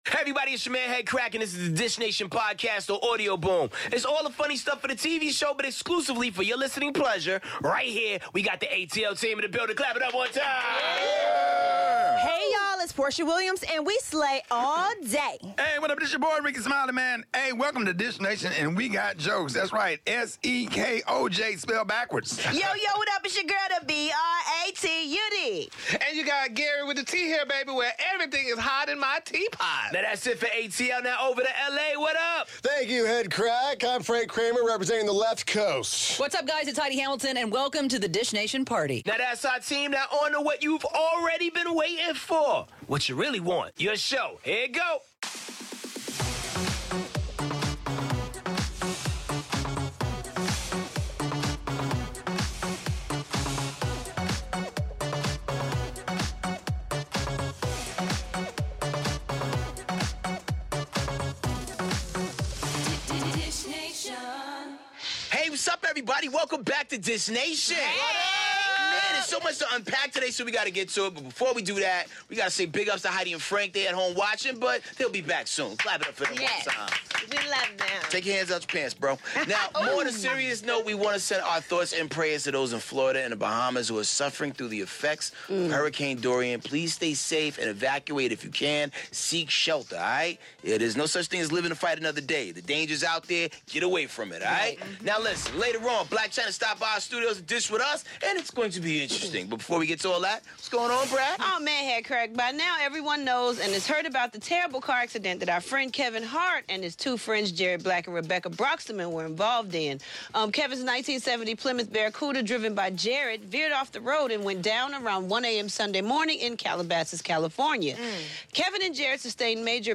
Garcelle Beauvais is a Real Housewife of Beverly Hills 🌴 Sasha Obama rushes off to college 🤓 and Blac Chyna is in the studio with us and she's spillin' the tea!